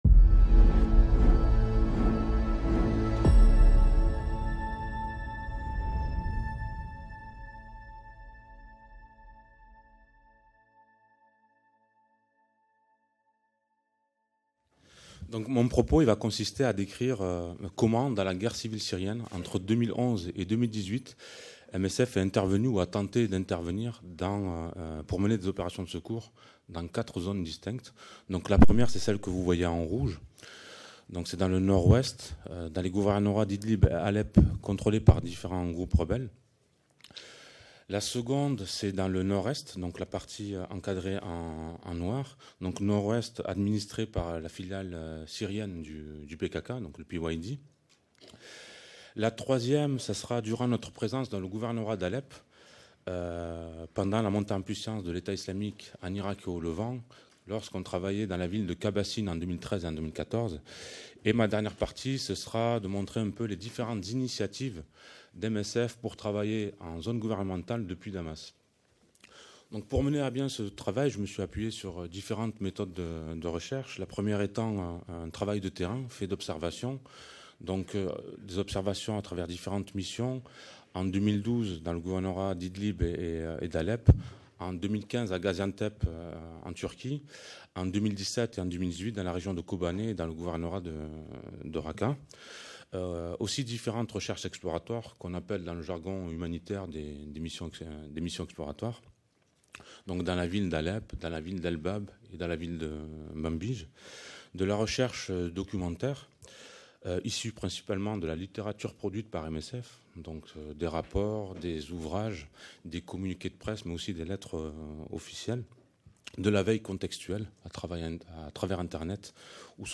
Violences extrêmes : enquêter, secourir, juger - Du 20 au 22 mars 2019 Syrie | Mercredi 20 mars 15:40 Les pratiques humanitaires de terrain à l’épreuve du conflit syrien |